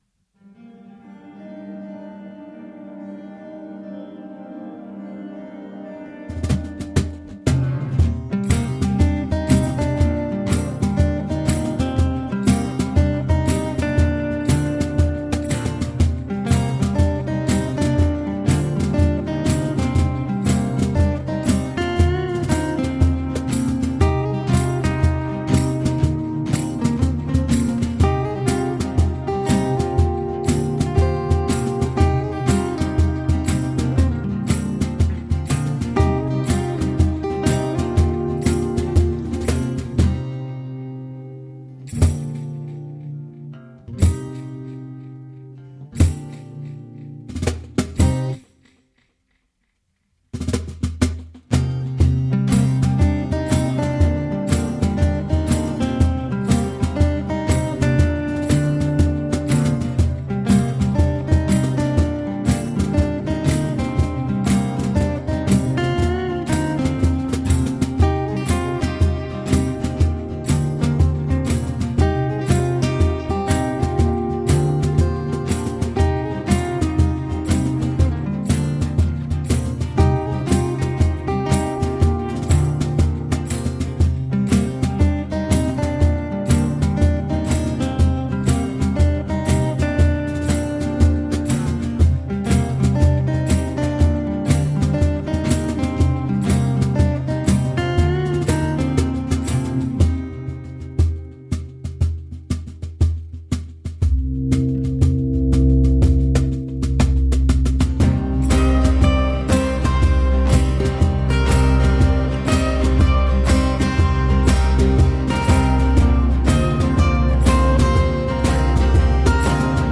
Relaxed pop and reggae music made in italy.